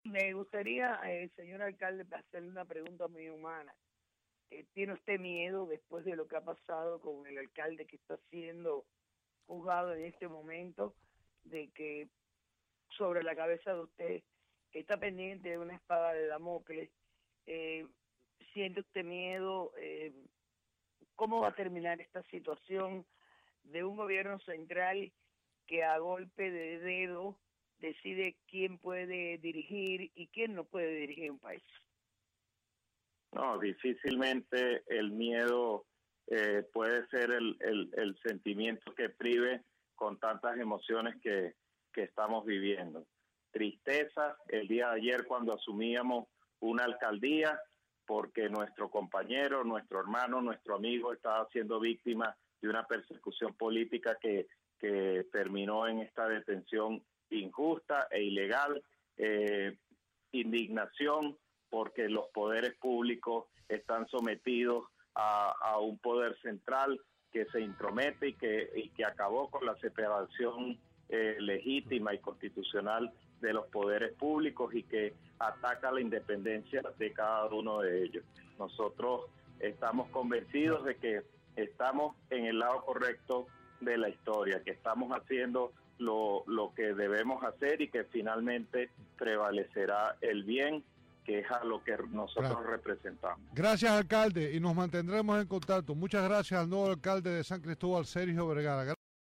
Declaraciones de Vergara